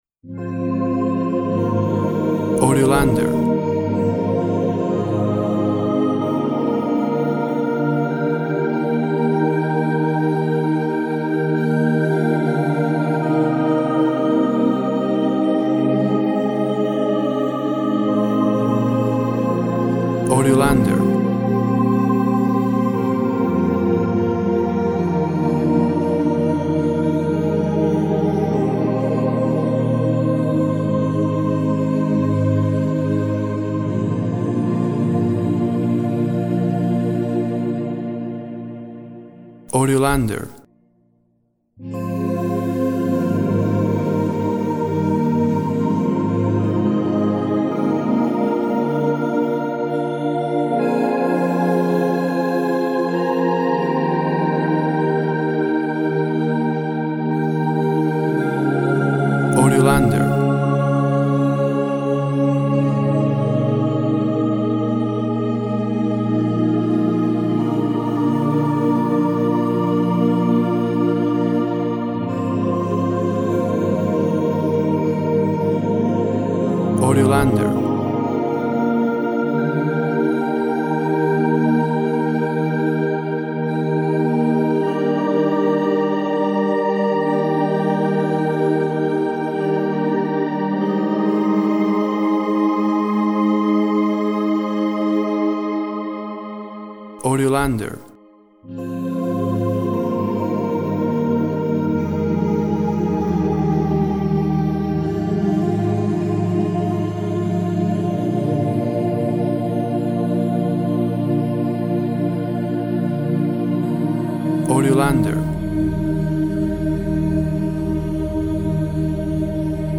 Synths create a sacred space for contemplation and hope.
Tempo (BPM) 48